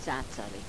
Cacari, pr. zázari,